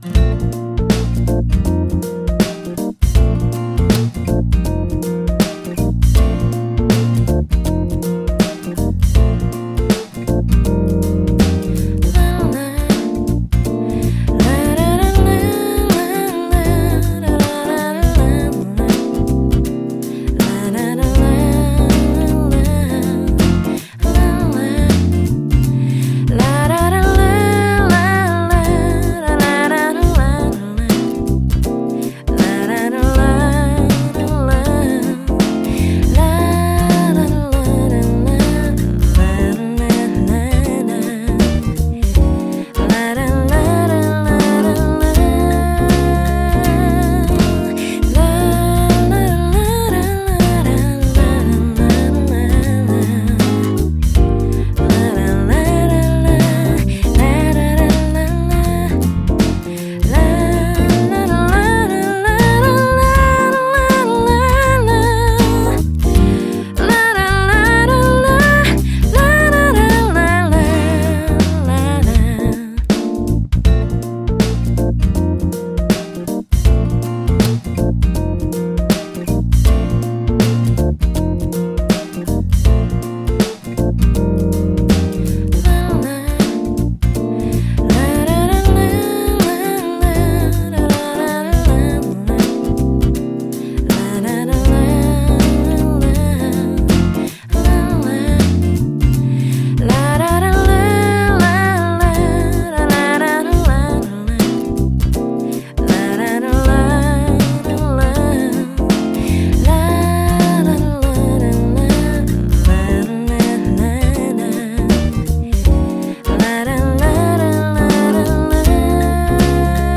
이 편곡은 뭐랄까 너무 예측이 가능해서 좀 뻔하기도 하거니와